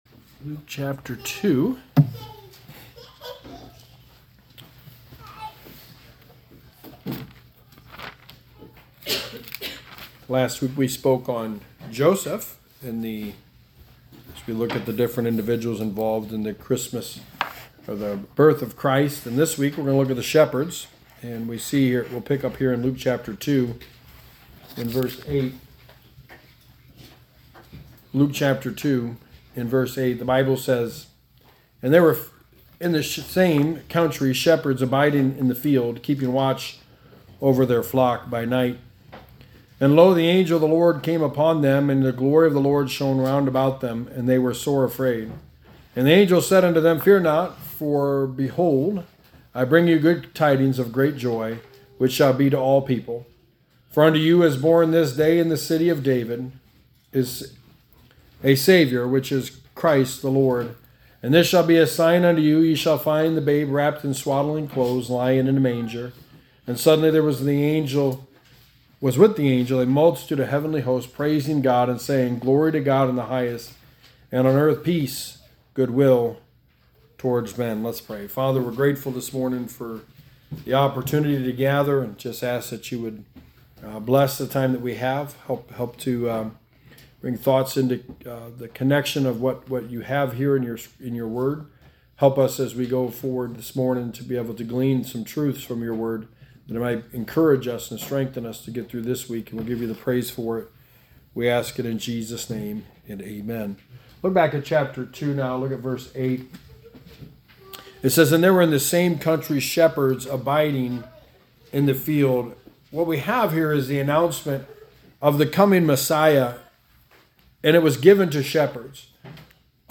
Sermon 2: The Birth of Christ – The Shepherds
Service Type: Sunday Morning